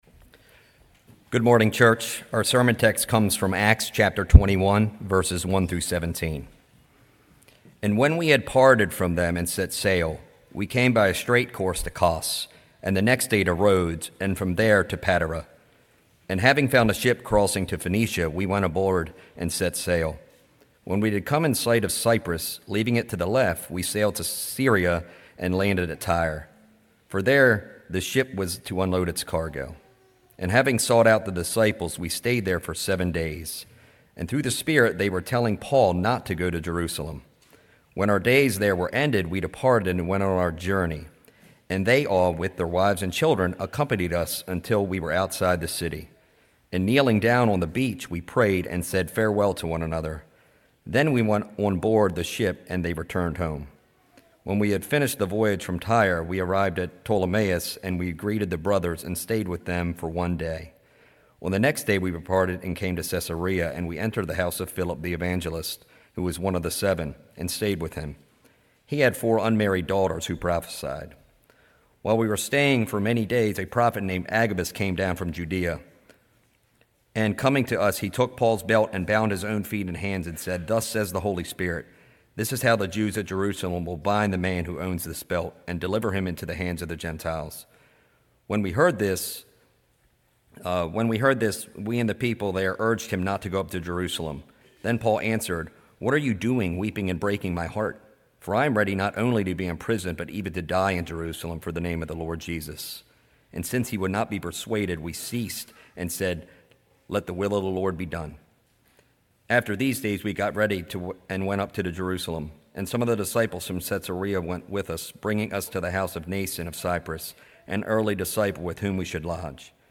sermon7.27.25.mp3